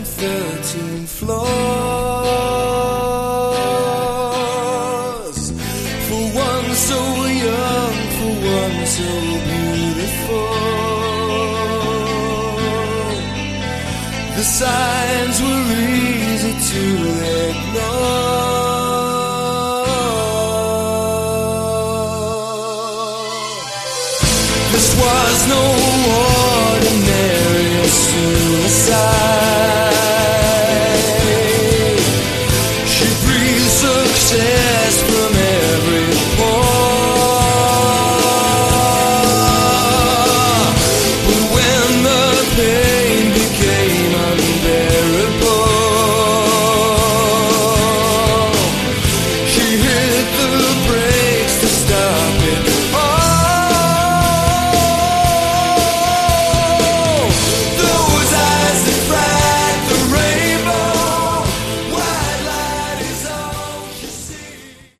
Category: AOR
lead and backing vocals
electric and acoustic guitars
drums, percussion
keyboards